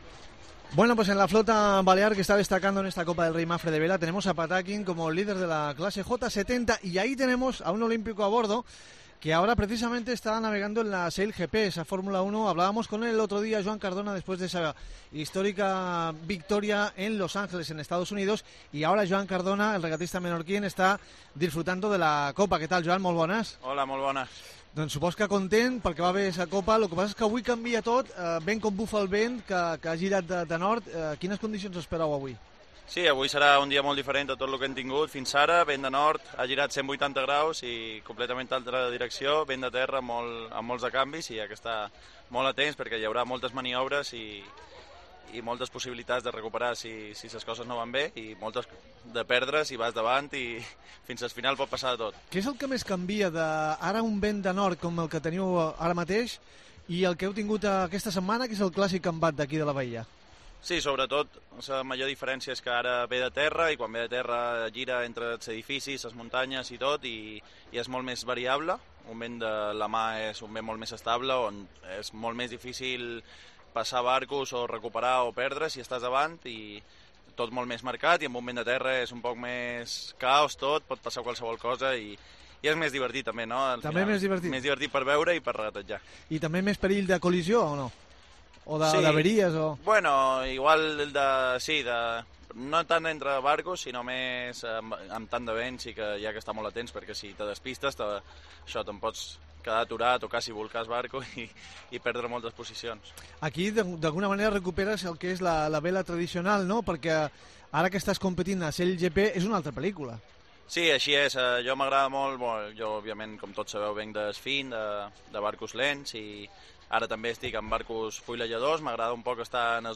El regatista balear visita el stand de Cope Baleares en el Real Club Naútico de Palma para analizar su participación en la Copa del Rey y como vive su año sin ir a París 2024